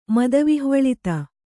♪ mada vihvaḷita